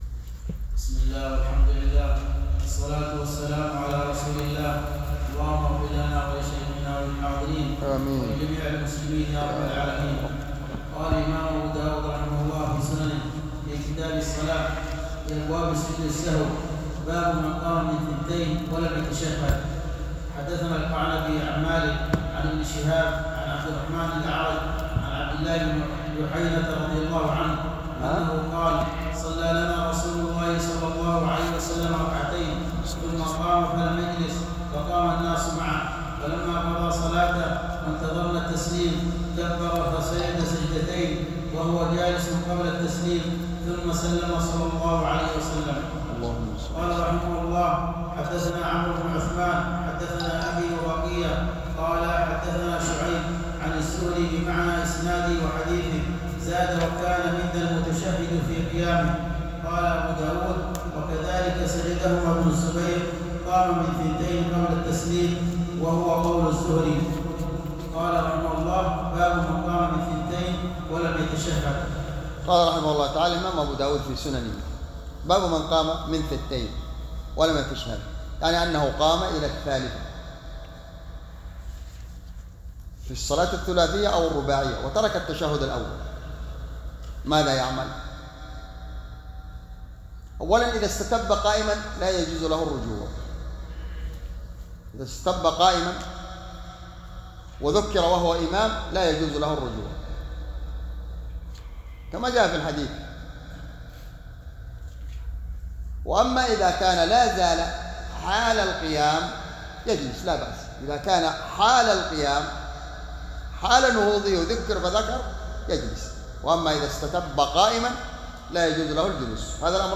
شرح كتاب الصلاة - سنن أبي داود | ١٢ شعبان ١٤٤٤ هـ _ بجامع الدرسي صبيا